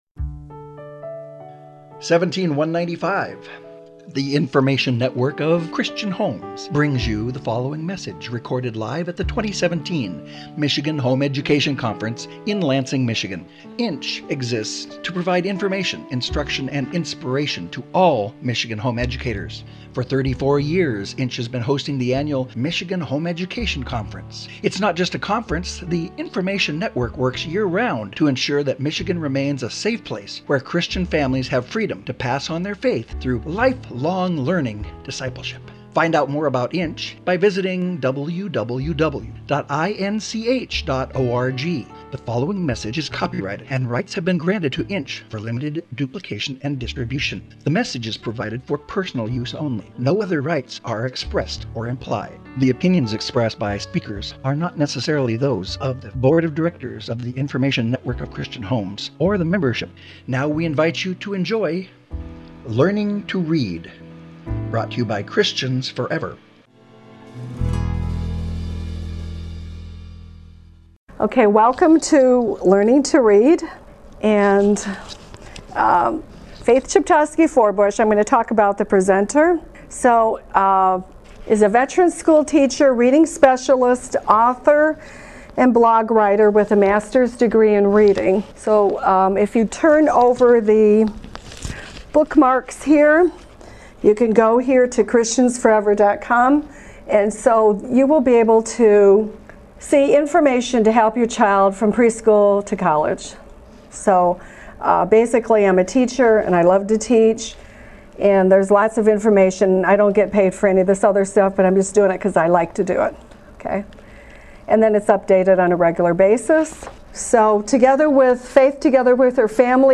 Resources for Homeschoolers presented at INCH:
Learning to Read Workshop.mp3